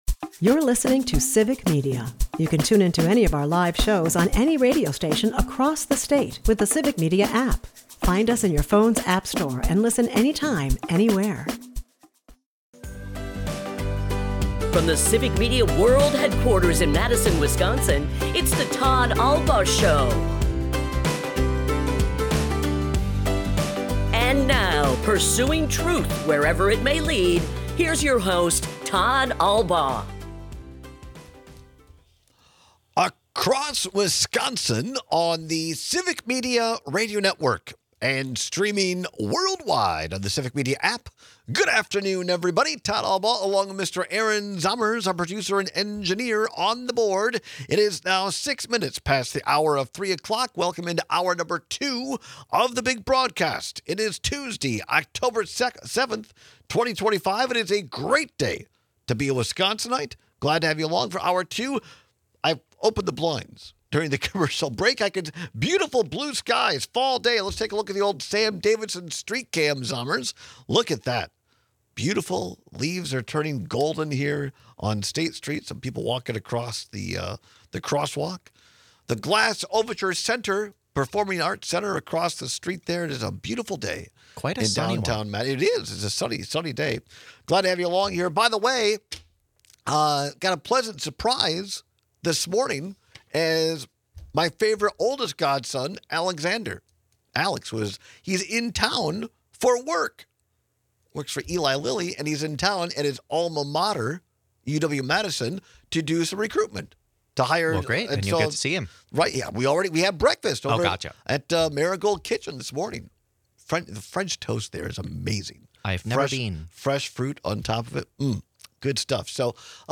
We take your calls and texts on which version of the messy hand food bothers you more.&nbsp